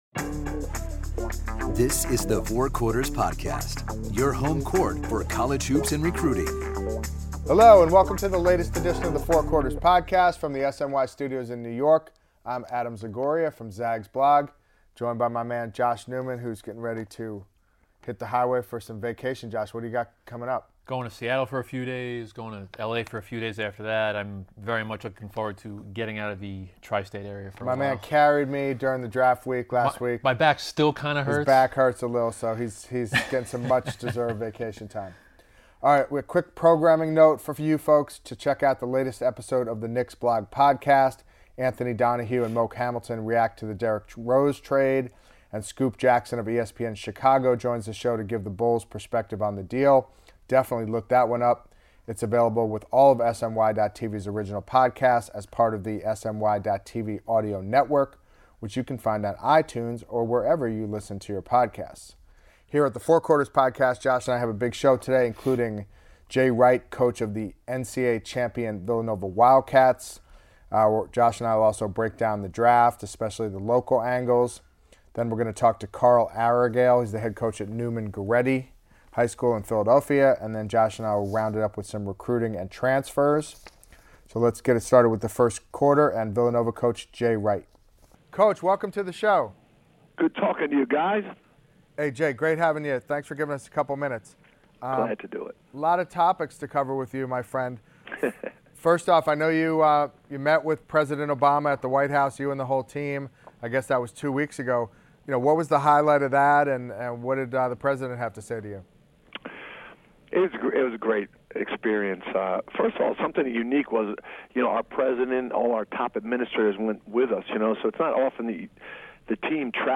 First up, Villanova head coach Jay Wright calls in to talk about the team’s meeting with President Barack Obama, Ben Simmons in Philly, Kris Dunn in Minny, next year’s Wildcats squad, and the future of the Big East.